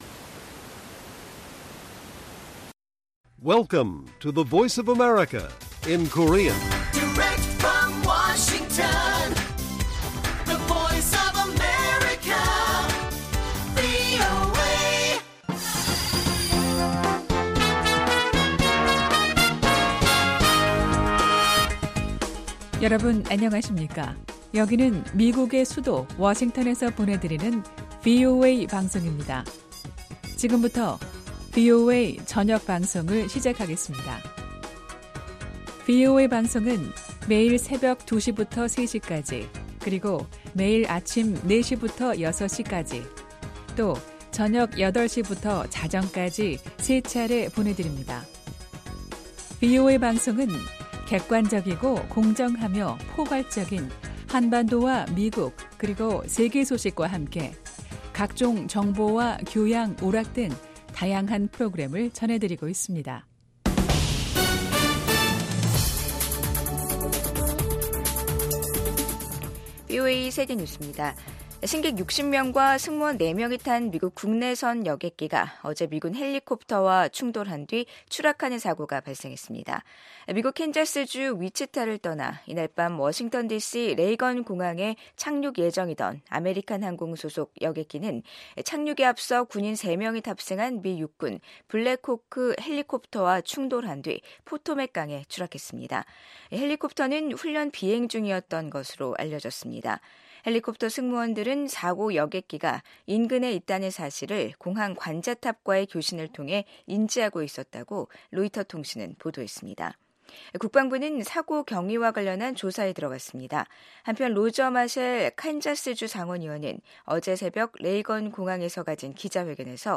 VOA 한국어 간판 뉴스 프로그램 '뉴스 투데이', 2025년 1월 30일 1부 방송입니다. 미국 의회에서 한반도 문제를 담당할 소위원회 구성이 완료된 가운데 한반도 정책에는 변화가 없을 거란 전망이 나옵니다. 도널드 트럼프 미국 대통령이 동맹과의 미사일 방어를 강화하라고 지시한 데 대해 전문가들은 미한 통합미사일 방어 구축 의지를 확인한 것으로 해석했습니다.